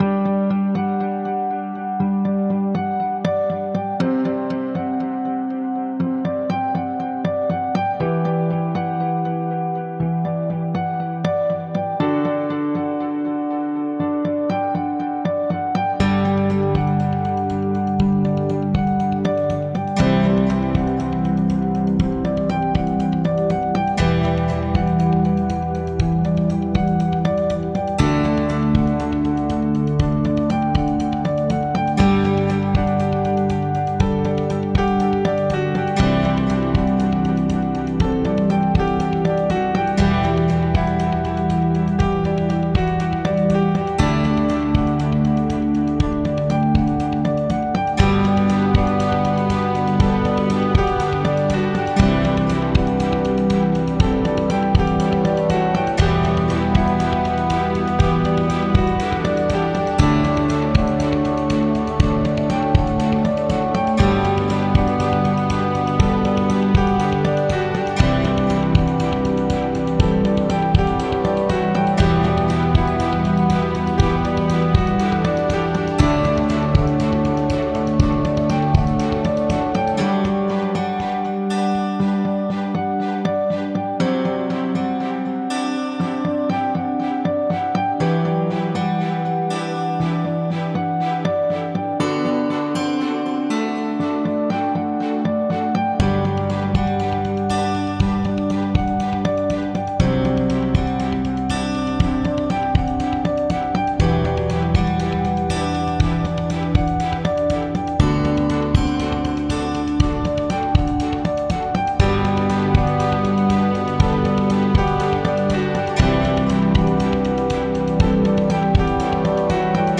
[صدای موسیقی آرام و ملایم]
موسیقی نمونه ملایم برای این بخش از مستند